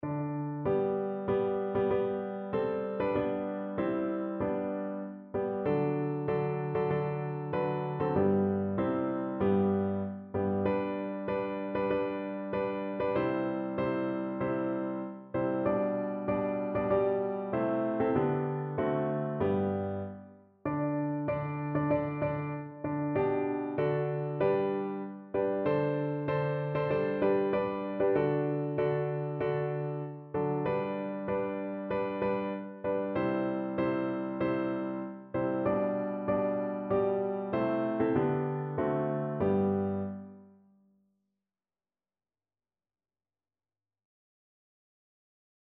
Evangeliumslieder Hingabe
Notensatz (4 Stimmen gemischt)